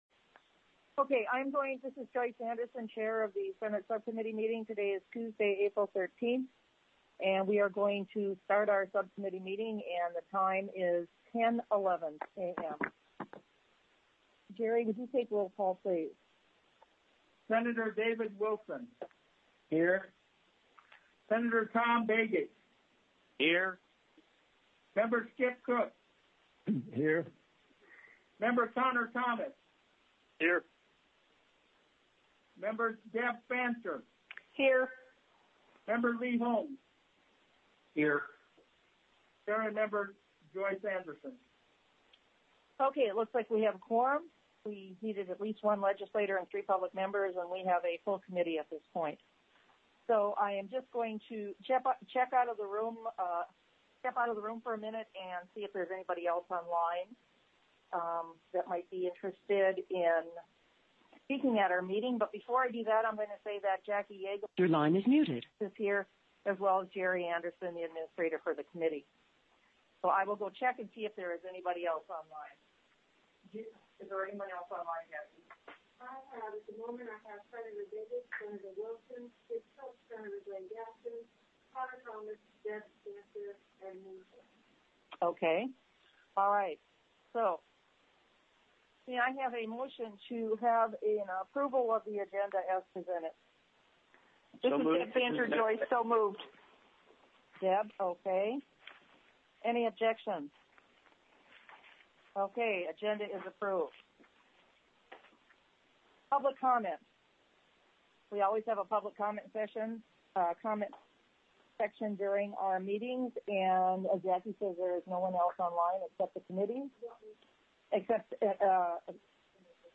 Legislature(2021 - 2022)BY TELECONFERENCE
The audio recordings are captured by our records offices as the official record of the meeting and will have more accurate timestamps.
Meeting will Be Teleconferenced: